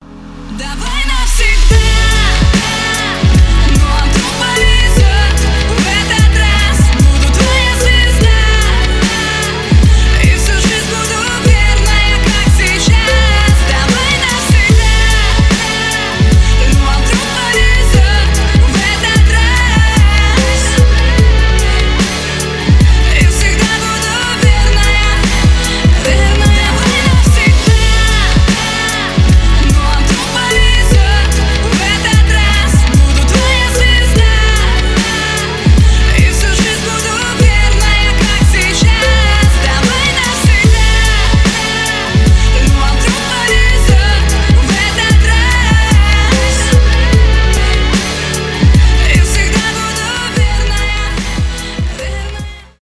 ремиксы
поп